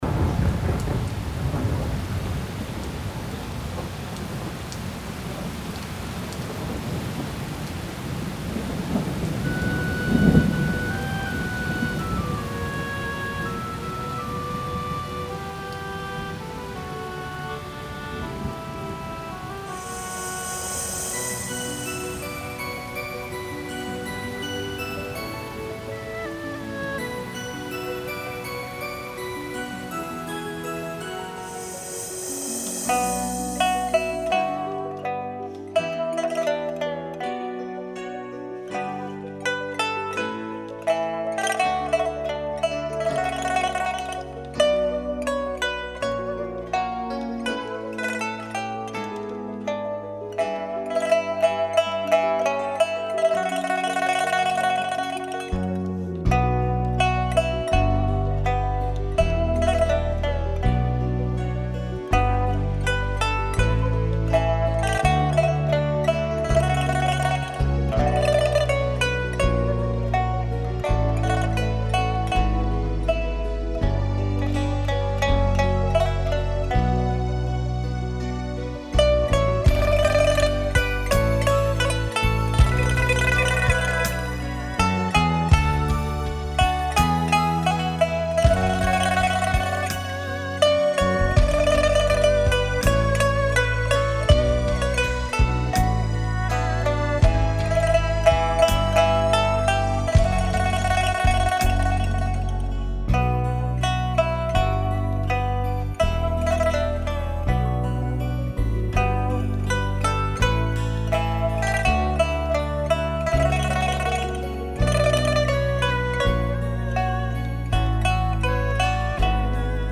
休闲音乐
纯净清新的大自然音乐，让您的心情获得轻松宁静。